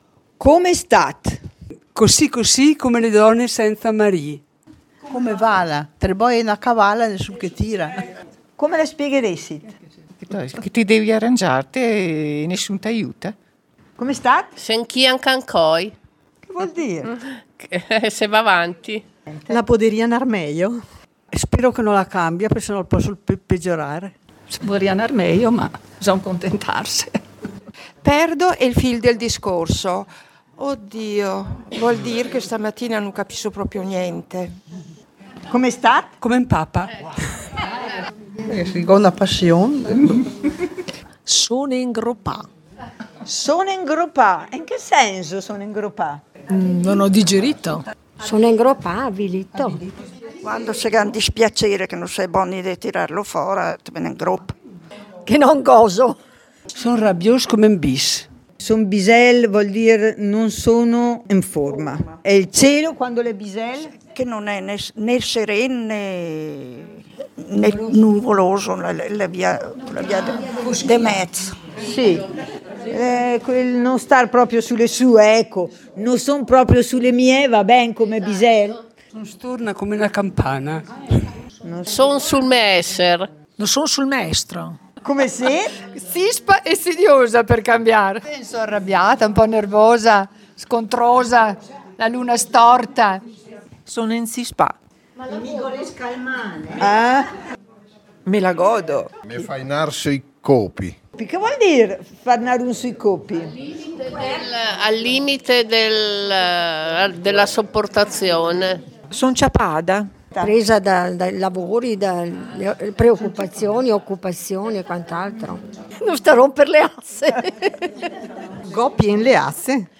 Terza esperienza di attività partecipativa all'interno di un incontro autogestito all'Università della terza Età e del Tempo Disponibile di Vezzano.
Seduti in cerchio chiunque poteva prendere la parola formulando una possibile risposta alla domanda "Come stat?" o "Come vala?" esprimendosi in dialetto trentino.
Registrazione ambientale